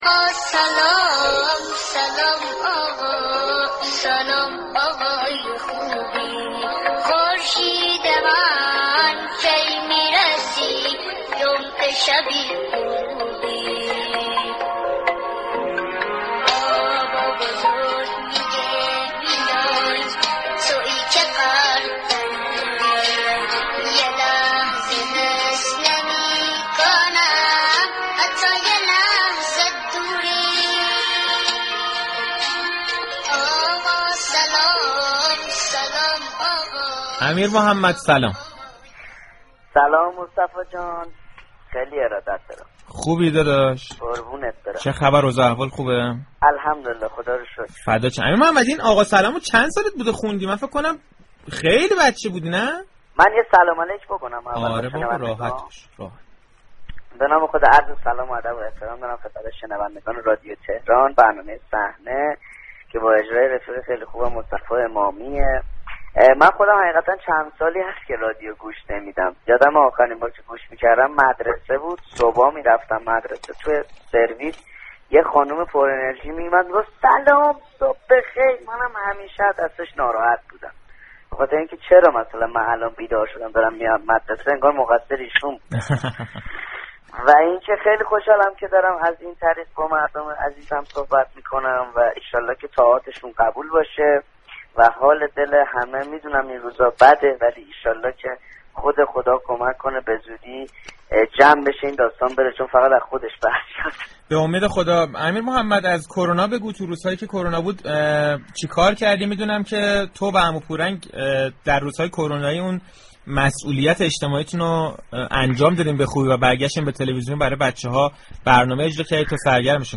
امیرمحمد متقیان، یكی از چهره‌های مجری بازیگر در برنامه‌های گروه كودكان تلویزیون، در گفتگو با صحنه‌، از علاقه‌اش به بازیگری گفته و عنوان كرد كه در این عرصه پرداختن به حرفه‌ی بازیگری را بیشتر از اجرا دوست دارد.